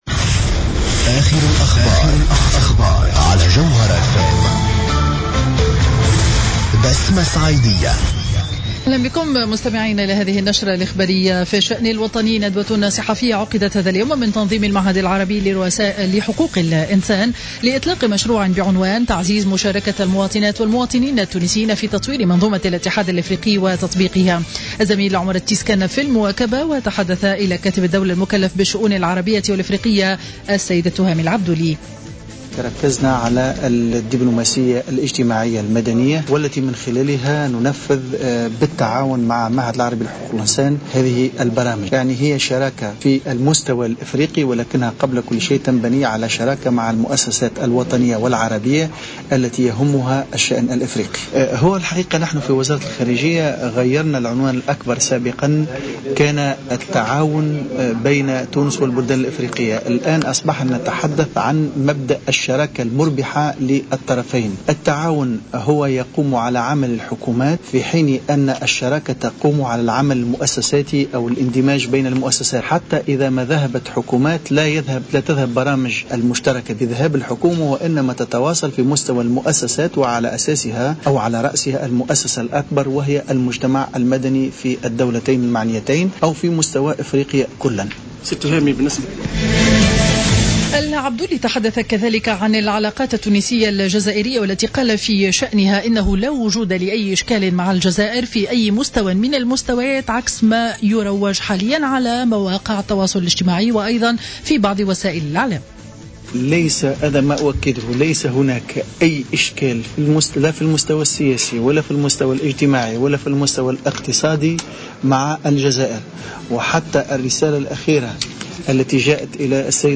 نشرة أخبار منتصف النهار ليوم الثلاثاء 21 جويلية 2015